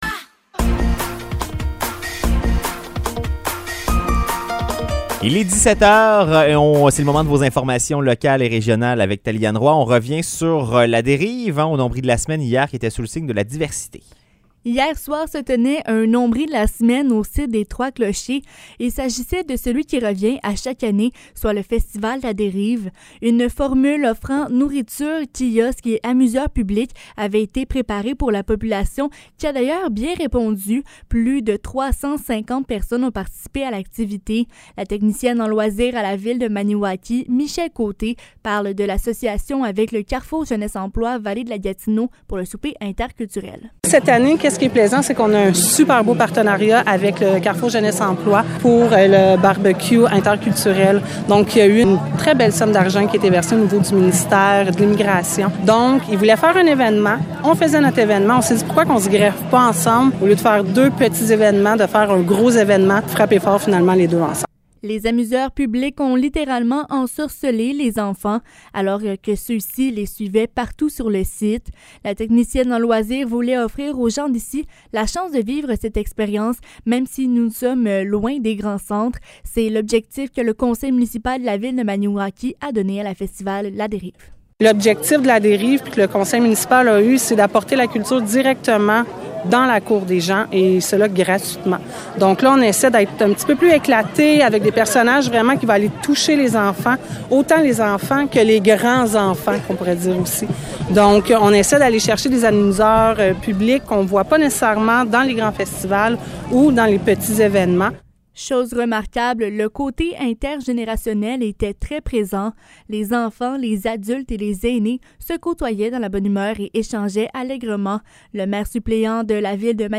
Nouvelles locales - 3 août 2023 - 17 h